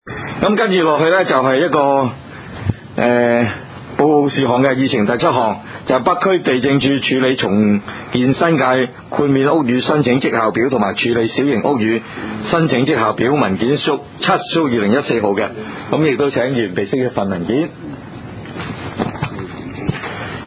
区议会大会的录音记录
北区区议会会议室